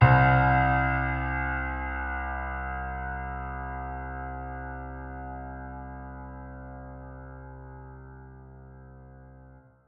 piano-sounds-dev
c1.mp3